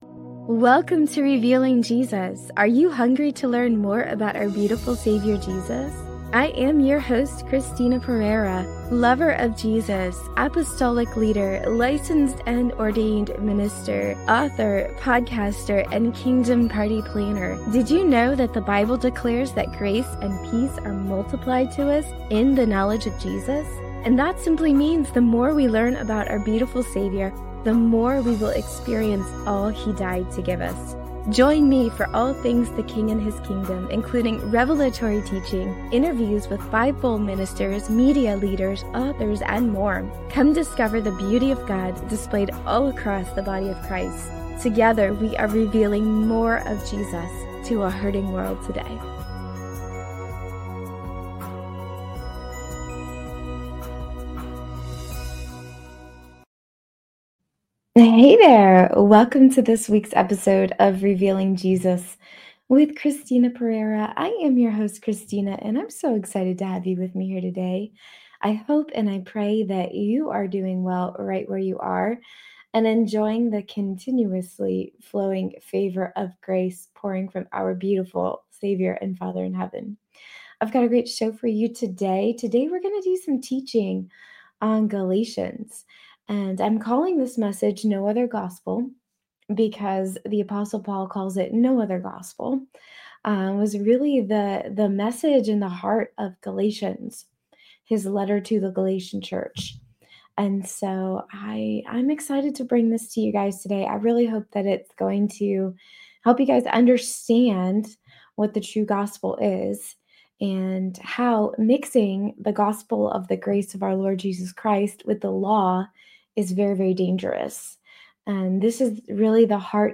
1 [Prédication] Dimanche 10 novembre 2024 : Mc XII, 38-44